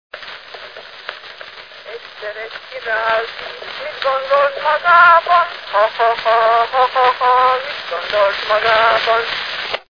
Dunántúl - Zala vm. - Resznek
ének
Gyűjtő: Vikár Béla
Stílus: 8. Újszerű kisambitusú dallamok
Szótagszám: 6.6.6.6
Kadencia: V (5) X 1